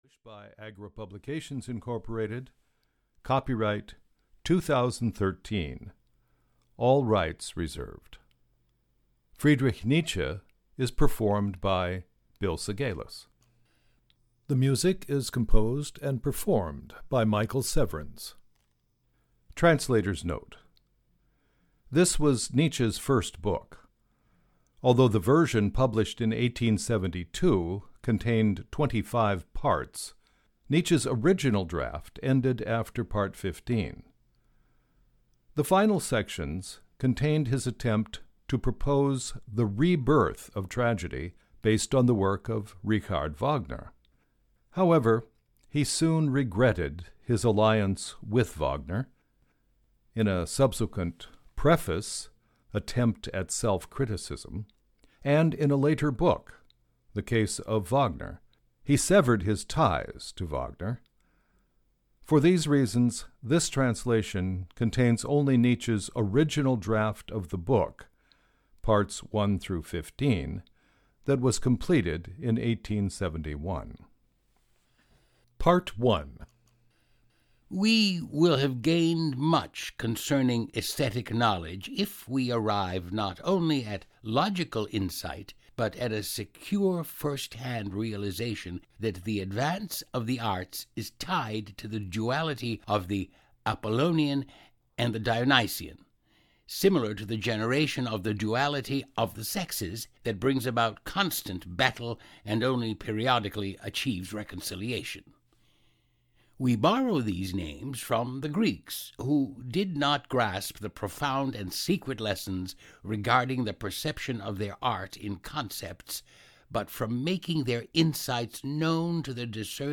Audio knihaNietzsche’s The Birth of Tragedy: From the Spirit of Music (EN)
Ukázka z knihy